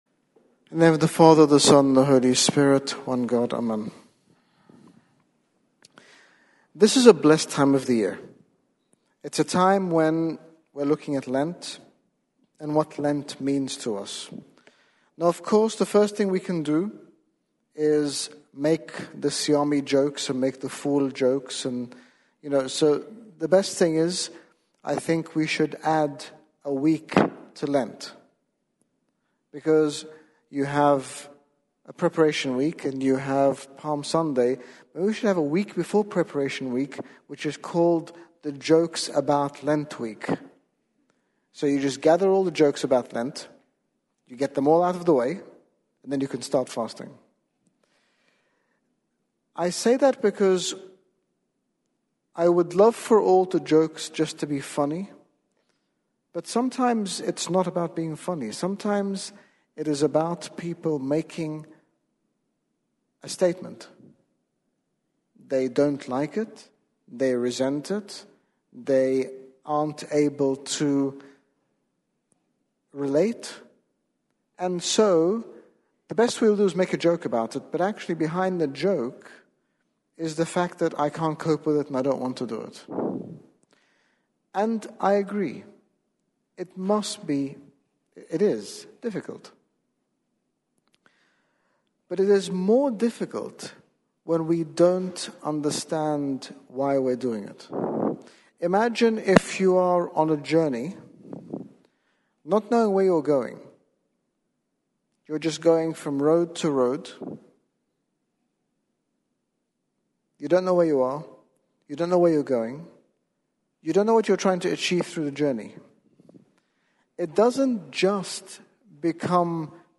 Date: 11 Feburary 2018 Gospel Reading: Matthew 6:1-18 In this sermon, Bishop Angaelos speaks about preparing our hearts during Lent by focusing on things like forgiveness, as opposed to being distracted by the technicalities of fasting.